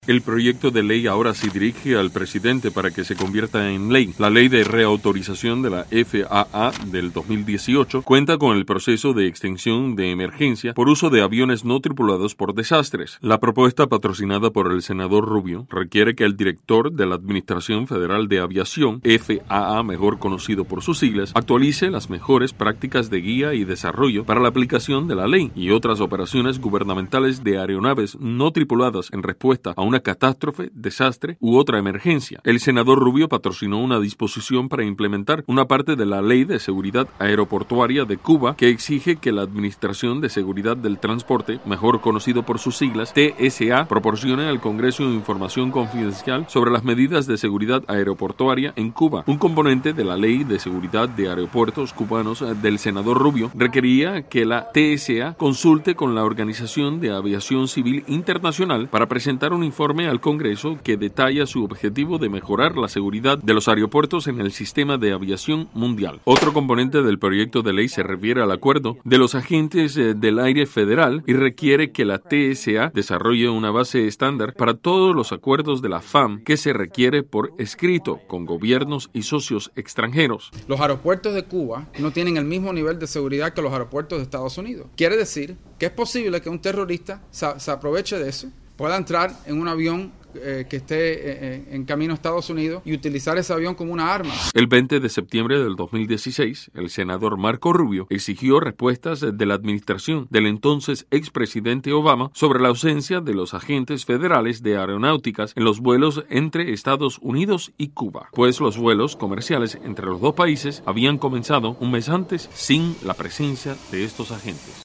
Reporte de Tomás Regalado para Televisión Martí